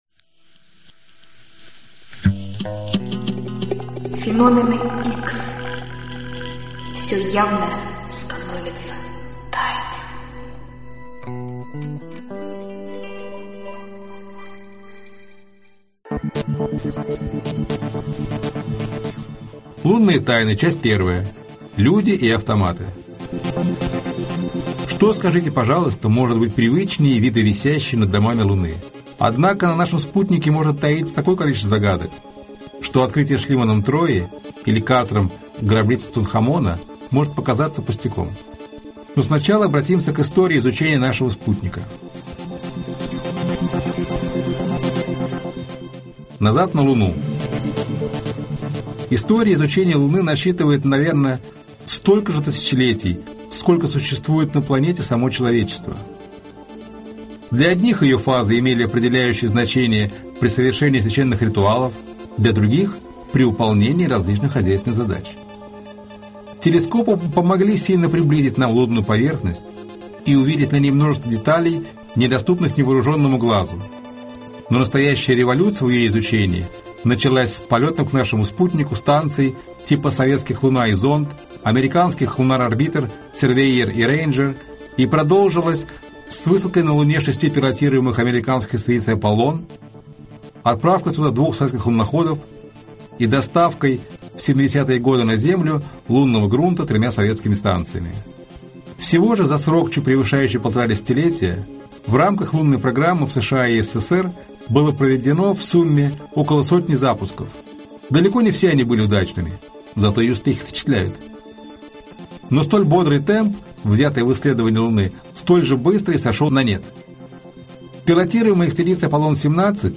Аудиокнига Тайны неба | Библиотека аудиокниг